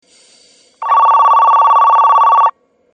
１番線発車ベル